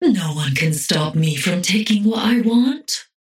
Paradox voice line - No one can stop me from taking what I want.